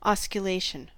Ääntäminen
IPA : /ˌɒs.kjʊˈleɪ.ʃən/